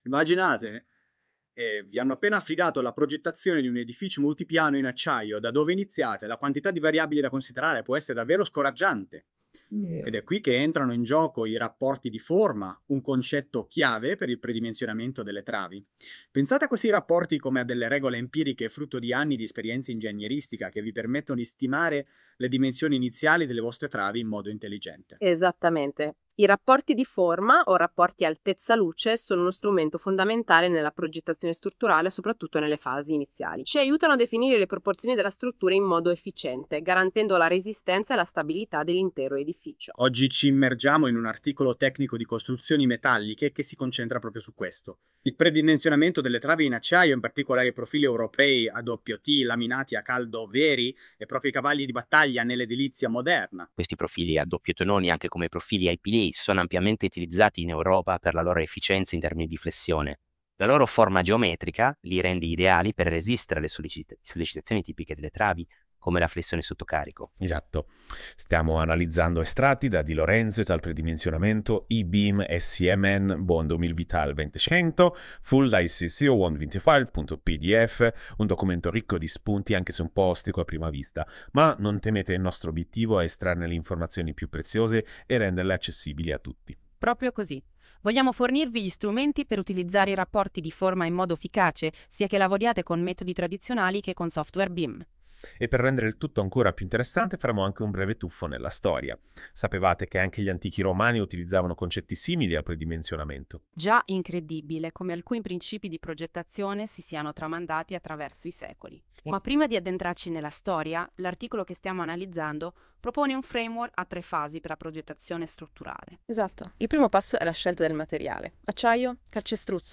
Tra questi, un breve podcast, realizzato con le tecniche dell’Intelligenza Artificiale, che riassume i contenuti dell’articolo e che potete ascoltare cliccando qui.
1_Podcast-Articolo-by-AI_Notebook.mp3